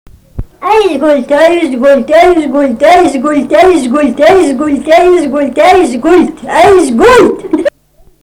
smulkieji žanrai